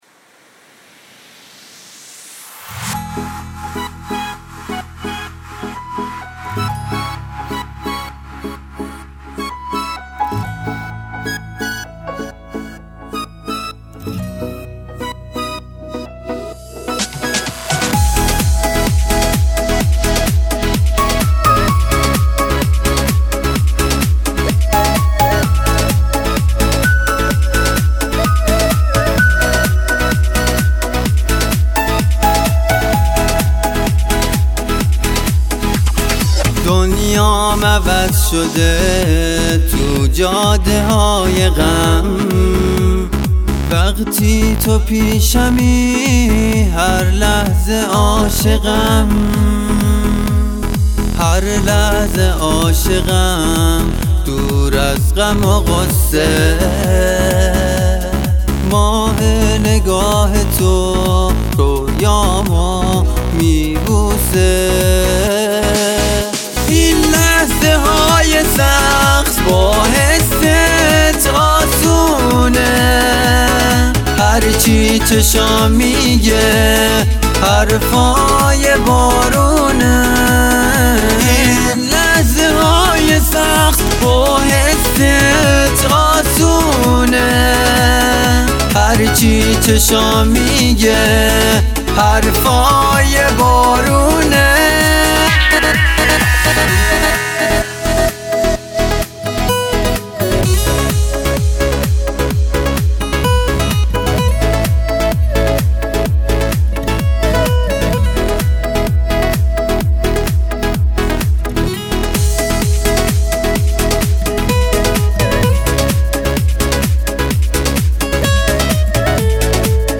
تنظیم خوبه ولی از اجرا راضی نیستم .